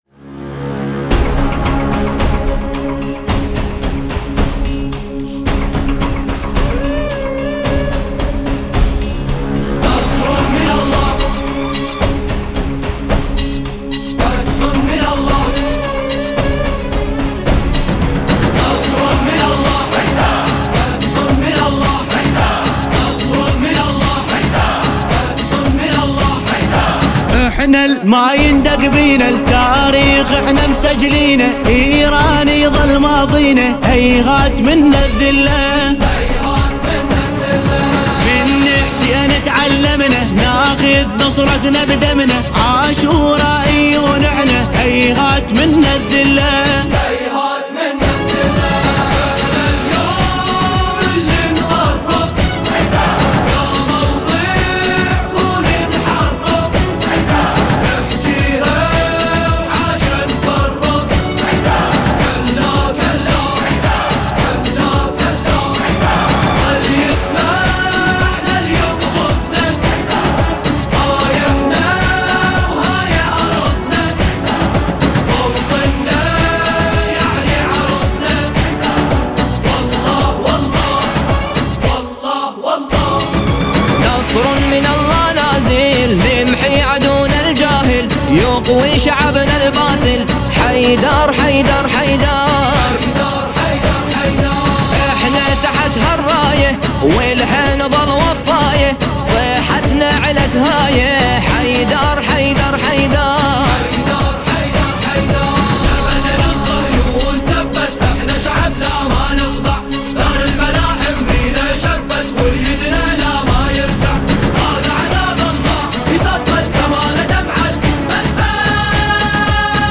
سرود ، سرود انقلابی